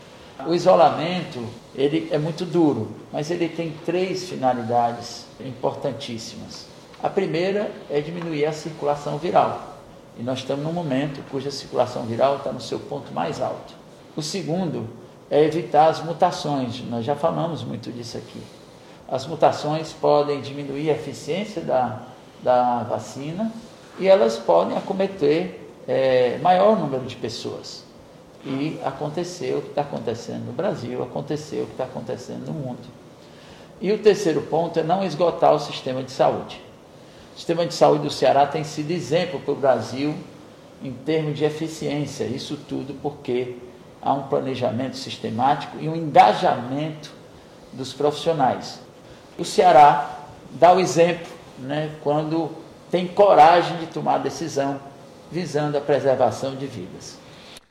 O secretário de Saúde do Estado, Dr Cabeto, falou da importância do isolamento social rígido, nesse momento.